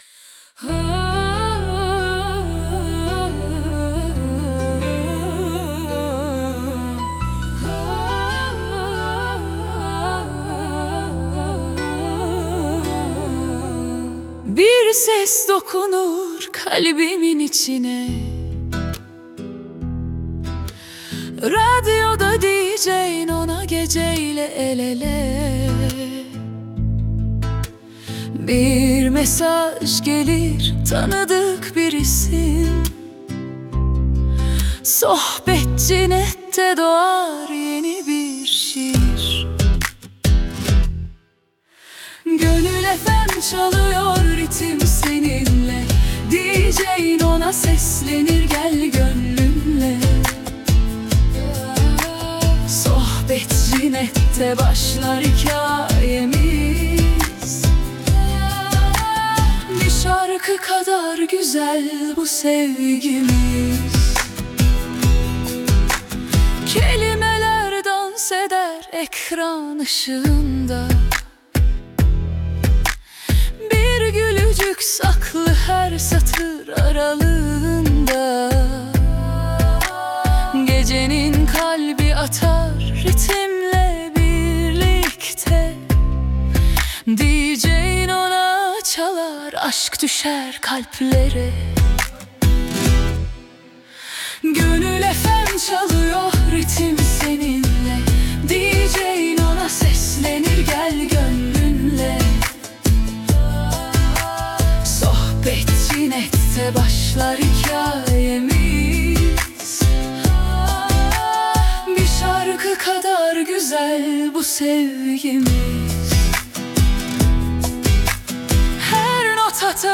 🎤 Vokalli 18.10.2025